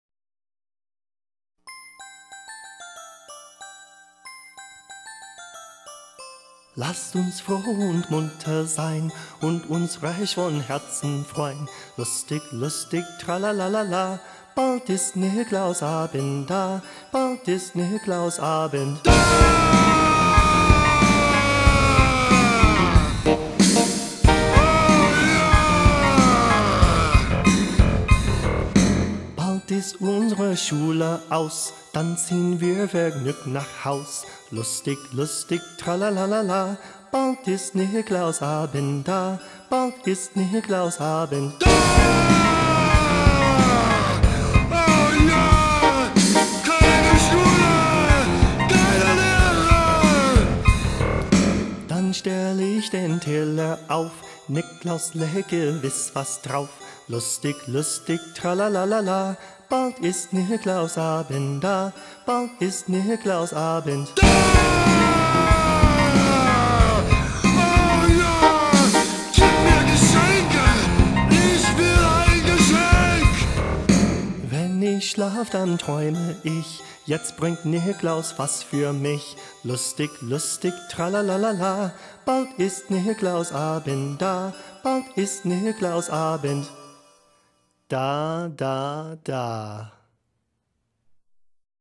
Click below for the rockin' St. Nicklaus Day Song: